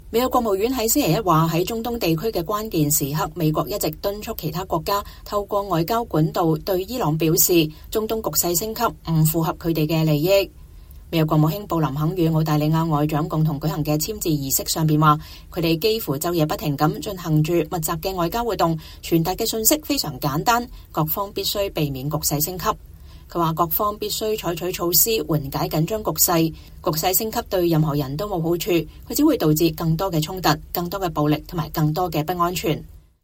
美國國務卿安東尼·布林肯在美國國務院舉行的2024年美澳部長級諮詢上發表演說。